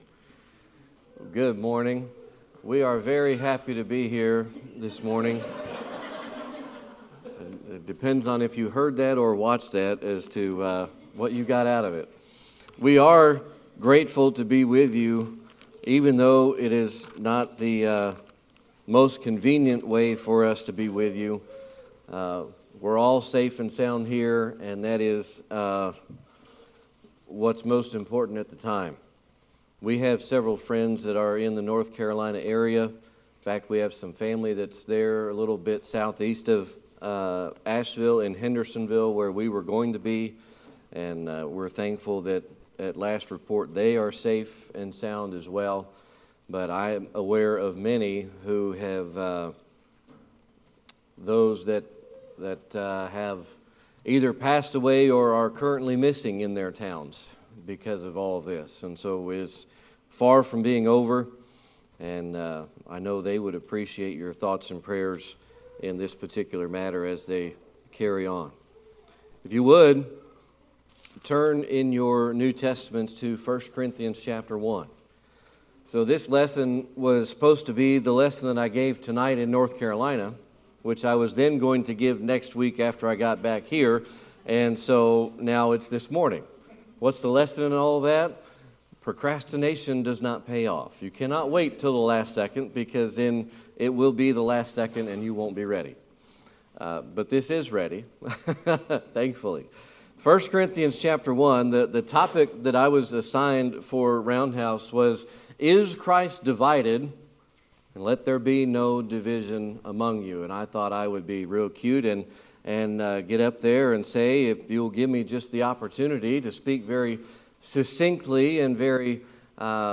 September 29, 2024 Series: Sermon Archives Passage: 1 Corinthians 1:10–17 Service Type: Sunday Morning Worship This sermon was originally prepared for a gathering in North Carolina, but circumstances have brought it to you today.